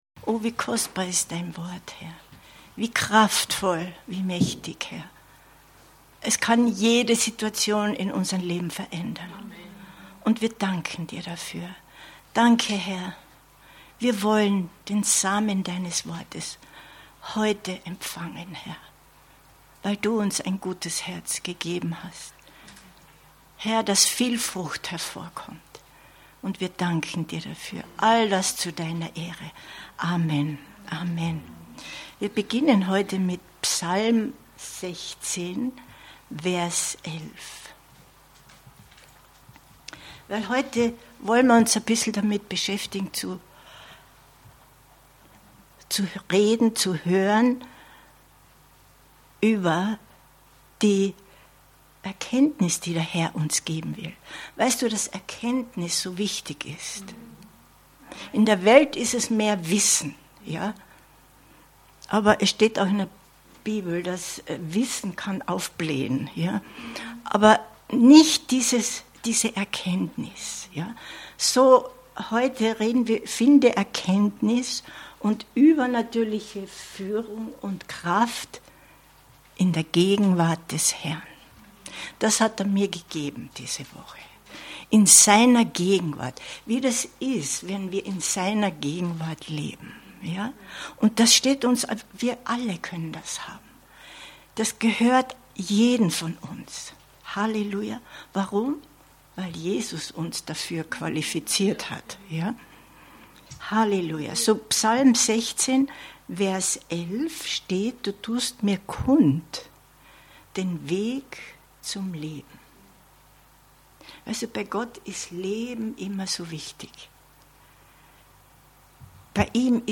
übernatürliche Führung und Kraft in der Gegenwart des Herrn 09.03.2025 Predigt herunterladen